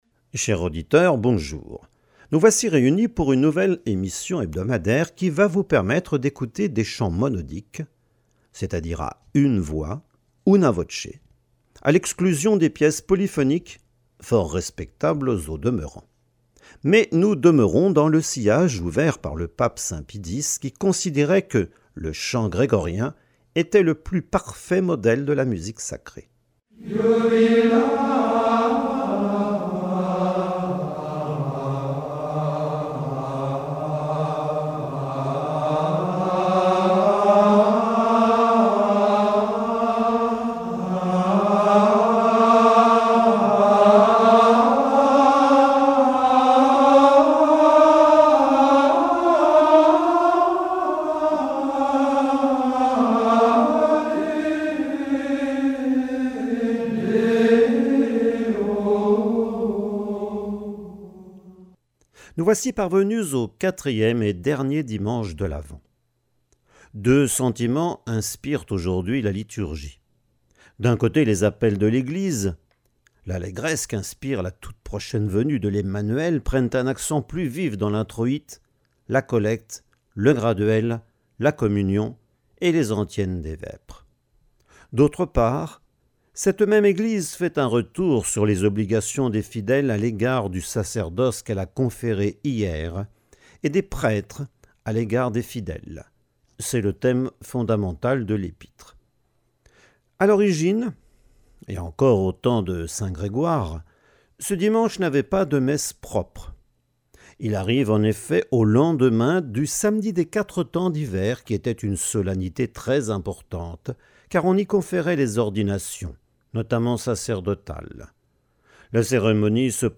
Vendredi saint in Parasceve - Fonction liturgique Abbaye Saint-Pierre de Solesmes (mai 1959) (28 min.)